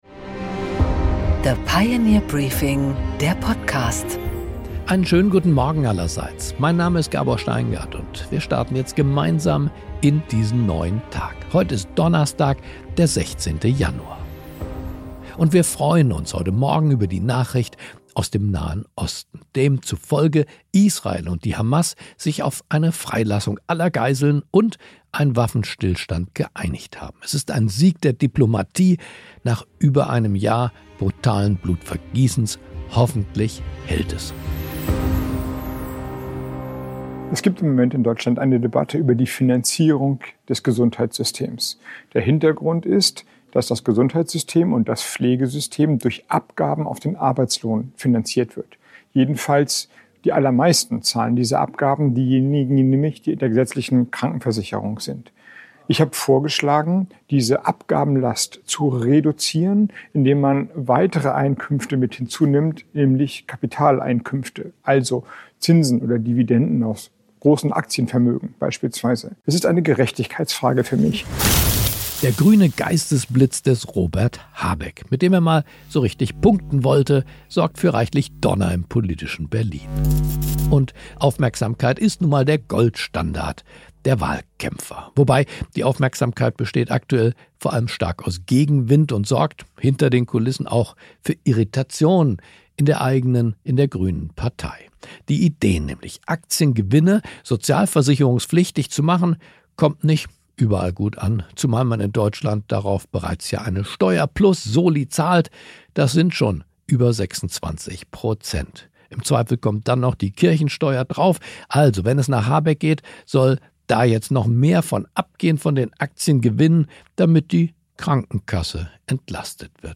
Gabor Steingart präsentiert das Pioneer Briefing
Im Interview: Alice Weidel, Kanzlerkandidatin der AfD, spricht mit Gabor Steingart an Bord der Pioneer Two über Migration, die CDU und die Entgleisungen von AfD-Politikern Maximilian Krah, Björn Höcke und Co. Das ausführliche Podcast Gespräch mit Alice Weidel finden Sie hier.